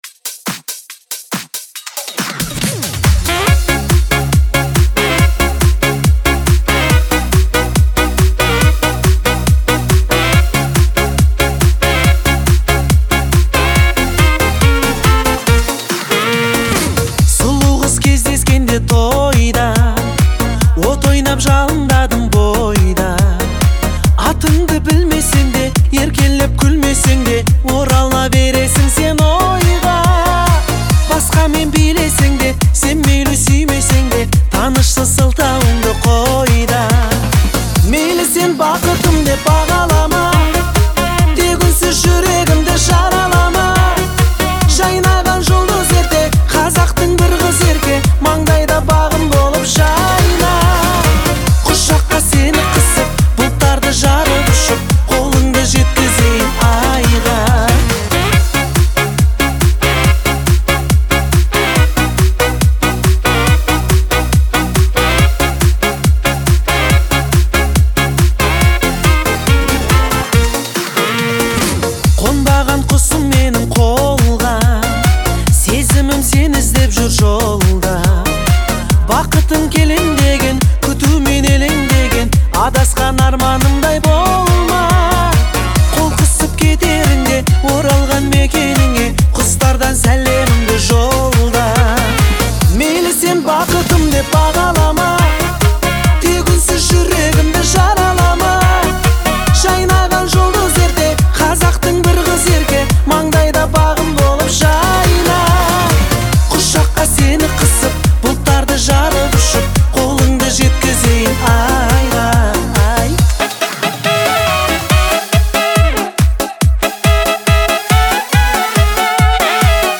это популярная казахская песня в жанре поп-фолк